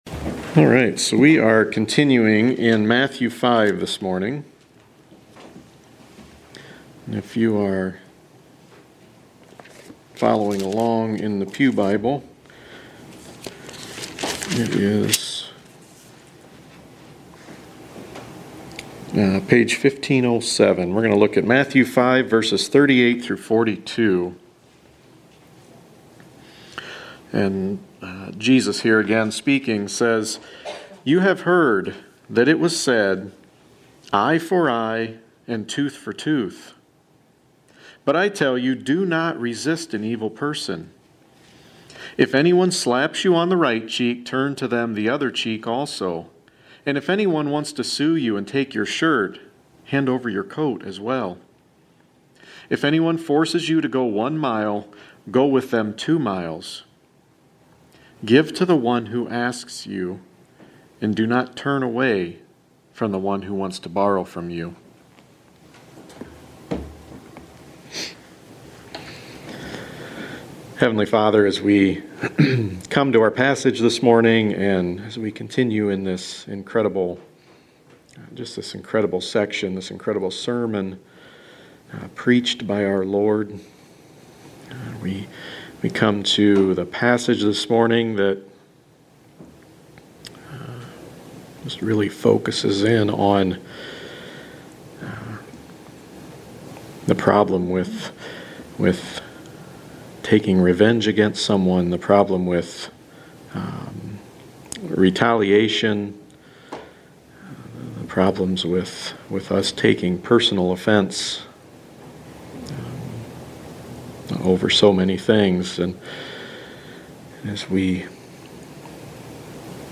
The Gospel of Matthew Series (25 sermons)